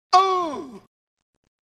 Tags: hip hop